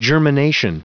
Prononciation du mot germination en anglais (fichier audio)
Prononciation du mot : germination